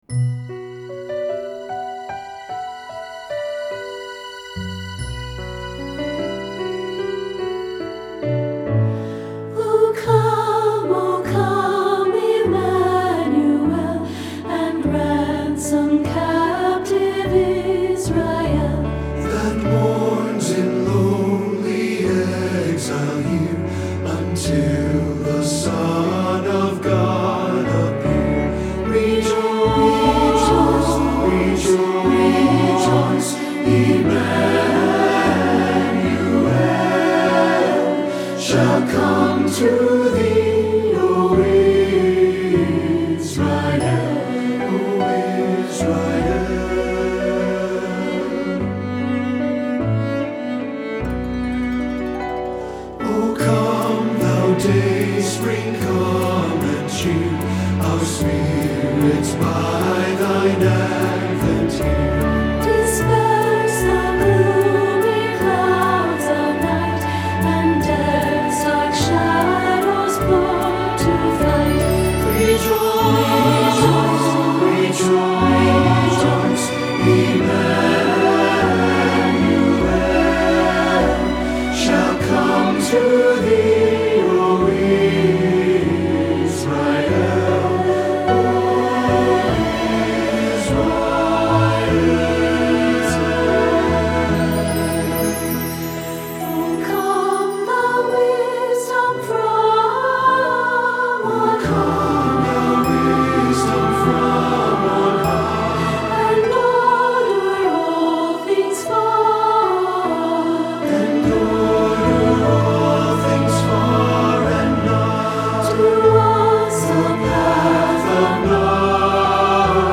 Adapted from plainsong
Voicing SATB